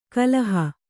♪ kalaha